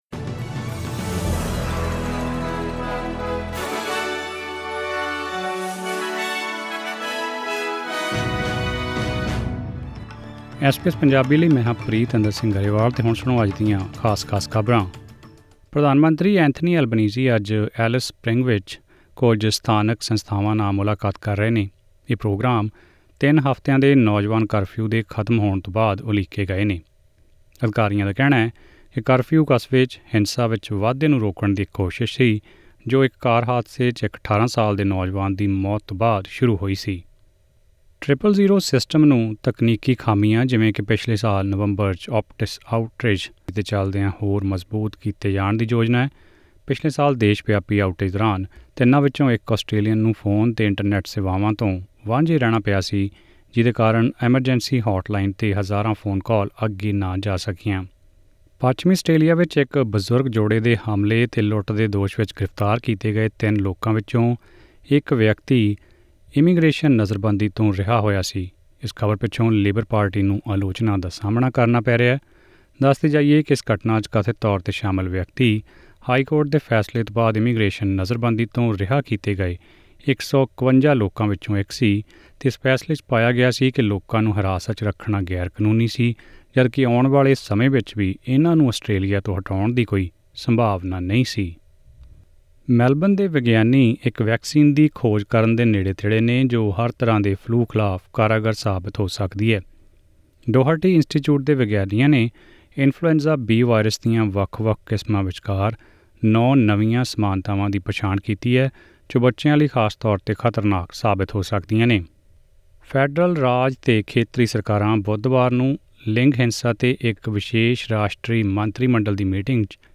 ਐਸ ਬੀ ਐਸ ਪੰਜਾਬੀ ਤੋਂ ਆਸਟ੍ਰੇਲੀਆ ਦੀਆਂ ਮੁੱਖ ਖ਼ਬਰਾਂ: 30 ਅਪ੍ਰੈਲ, 2024